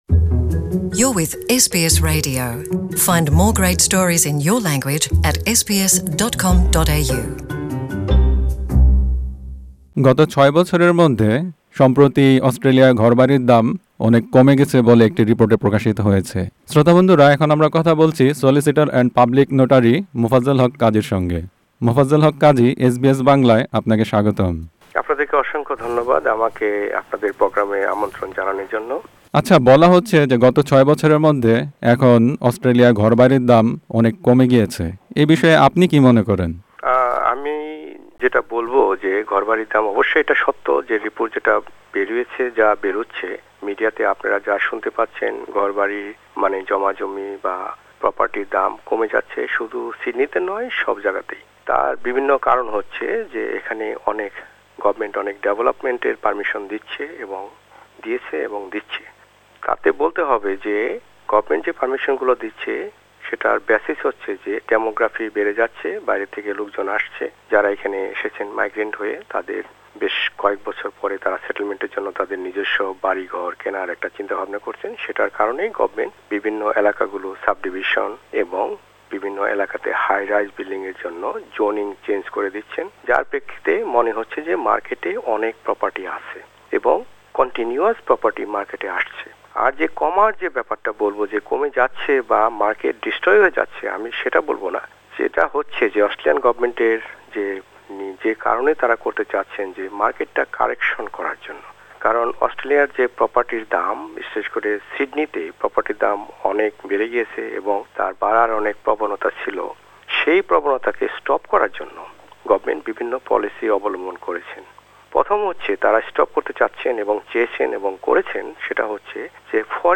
House prices across Australia have fallen for the first time in six years. A quarterly report by the property-classifieds group Domain shows some property values in Sydney, for example, have fallen by more than 10 per cent in the past year. SBS Bangla spoke with one solicitor & public notary, one lender and one real estate consultant.